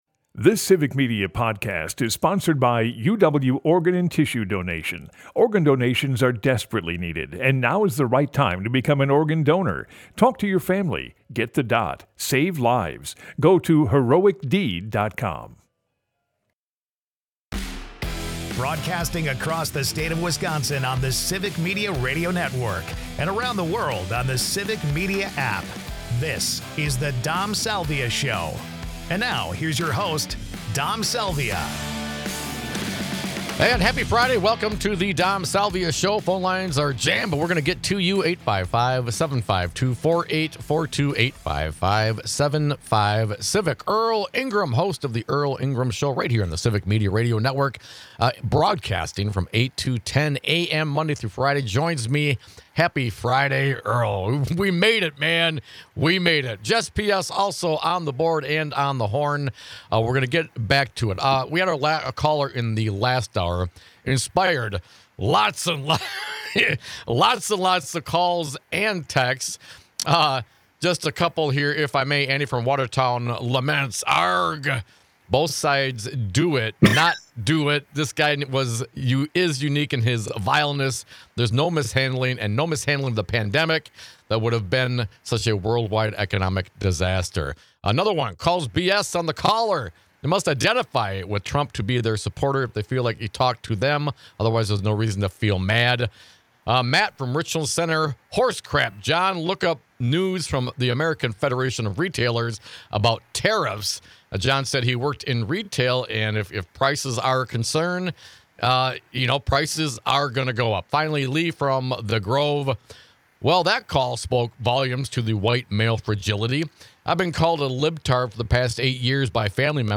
We continue our post-mortem of the 2024 Presidential election with our callers.